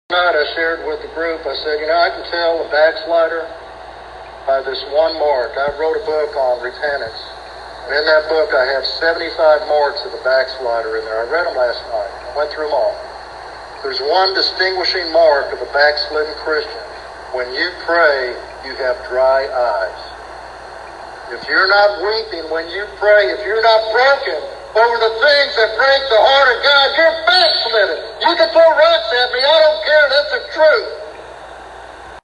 This sermon encourages listeners to embrace genuine repentance and renew their spiritual vitality through heartfelt prayer.